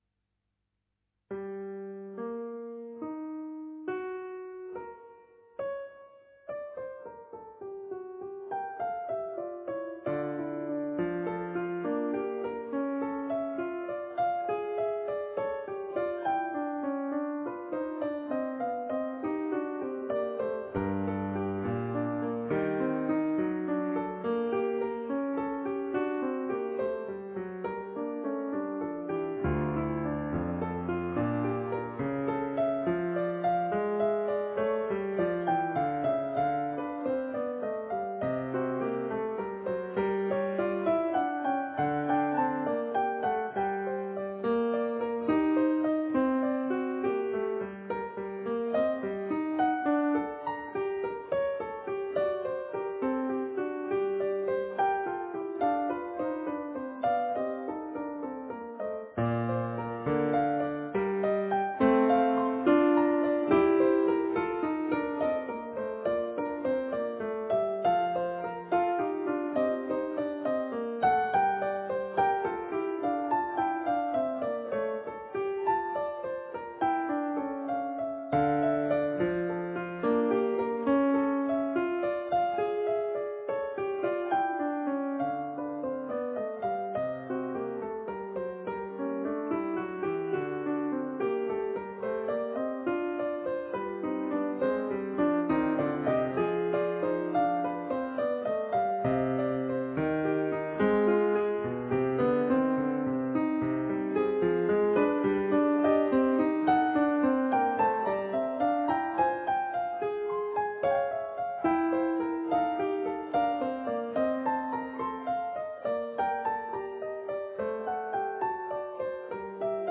La sonata en sol menor K. 30 (Longo L. 499), de Domenico Scarlatti, conocida como «Fuga del gato», es una sonata bipartita para clavecín en un movimiento con indicación de tempo moderato y estilo fugado.
piano